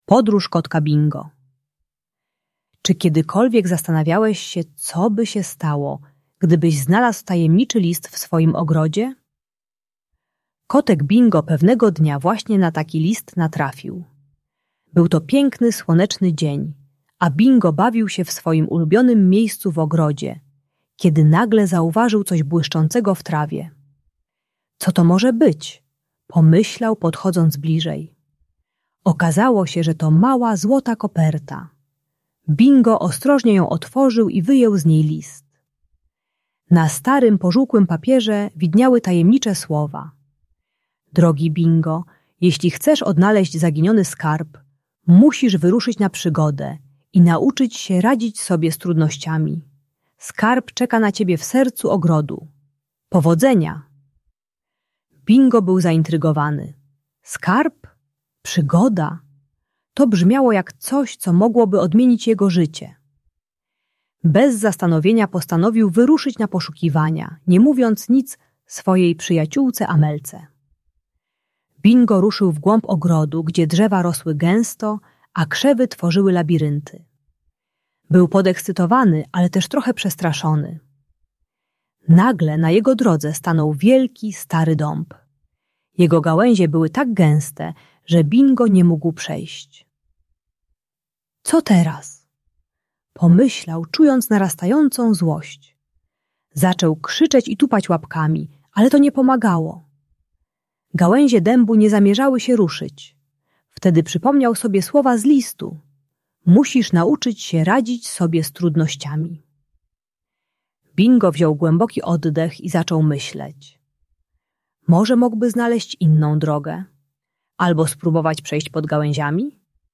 Podróż Kotka Bingo - Niepokojące zachowania | Audiobajka